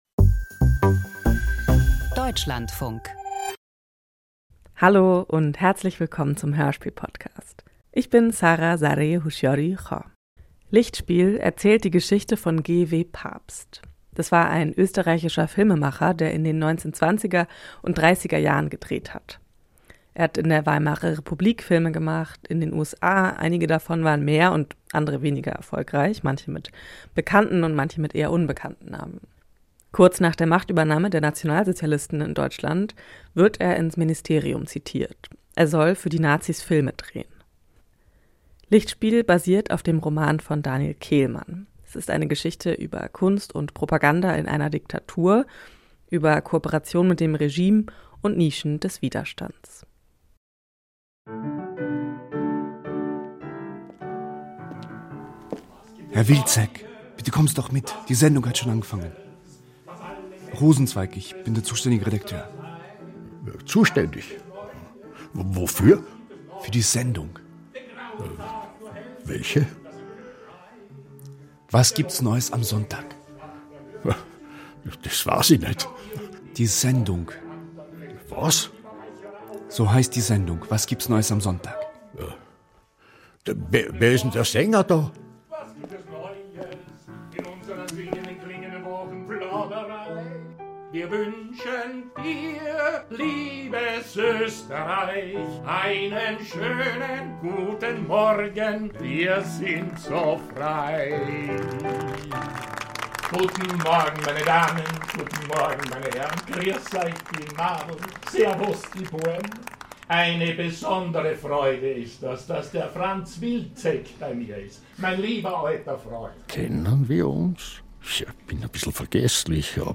Lichtspiel - Hörspielserie nach Daniel Kehlmann